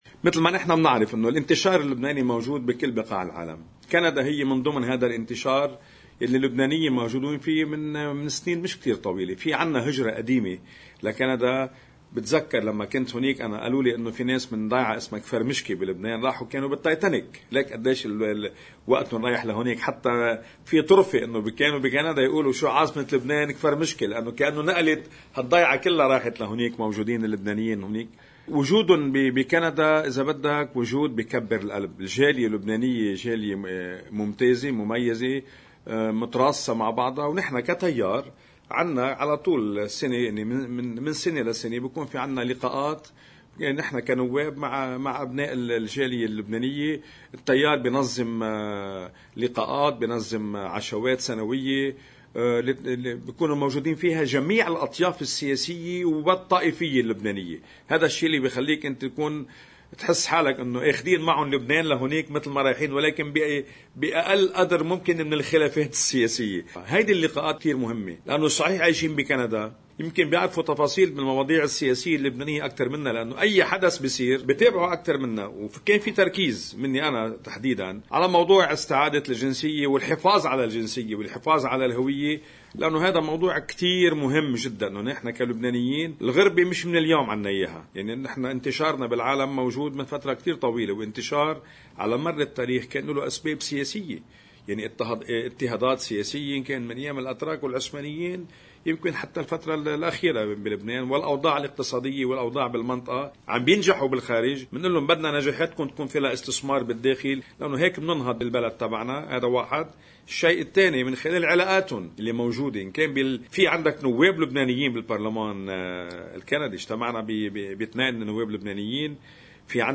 مقتطف من حديث عضو تكتّل التغيير والإصلاح النائب أمل أبو زيد لقناة الـ”OTV”: